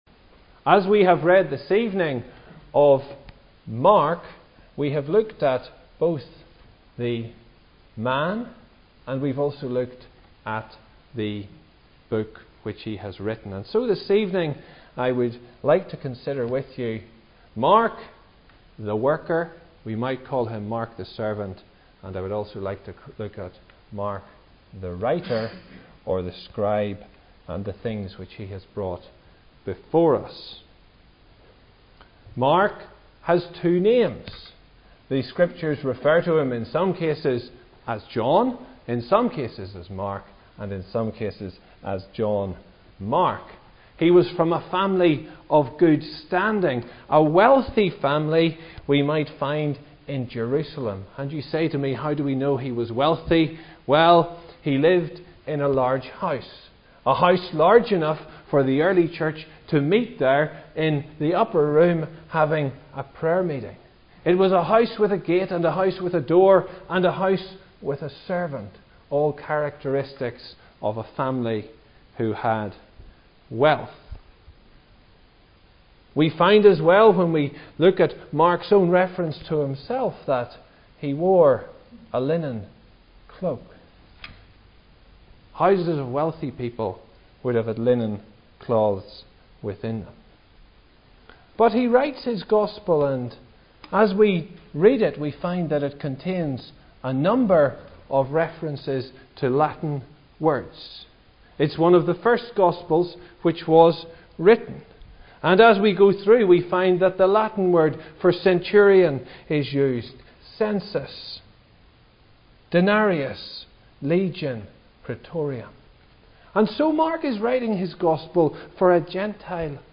The unique features of Mark’s gospel are also helpfully spelt out in this message (Message preached 30th Apr 2015)